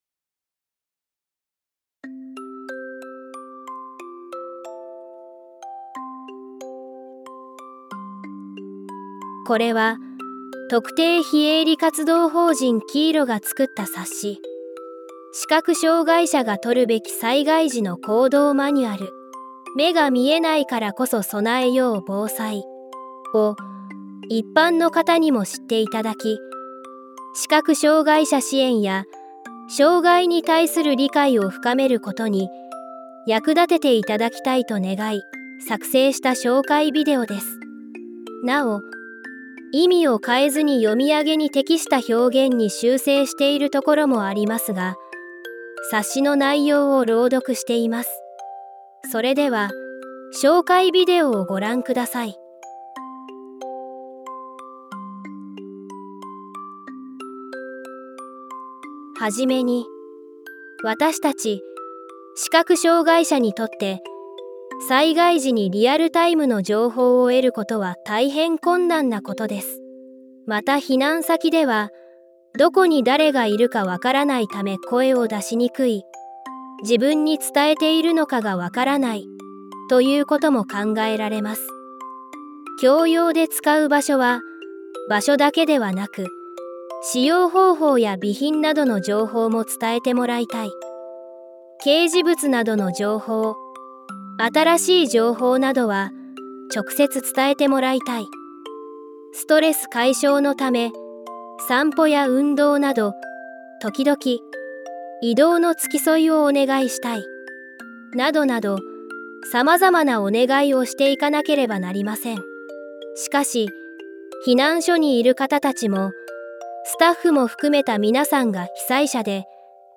bousairoudoku.mp3